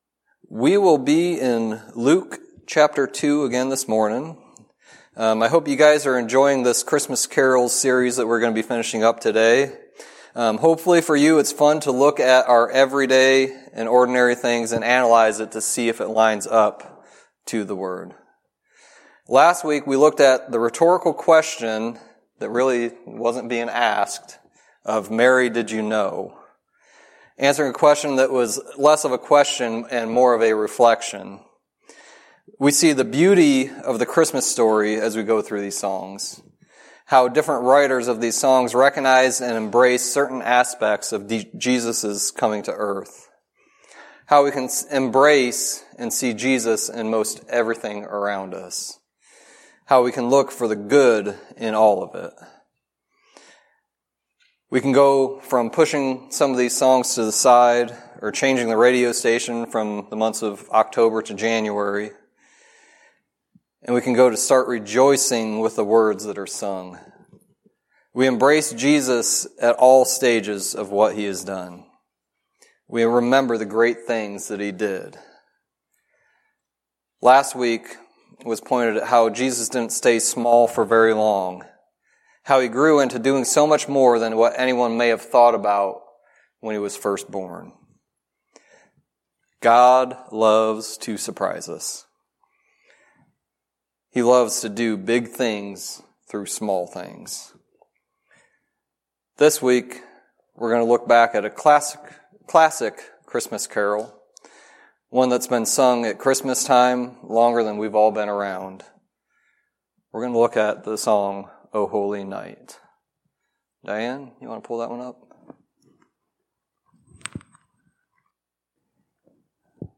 Sermon messages available online.
Luke 2:1-20 Service Type: Sunday Teaching God can redeem anything for His glory.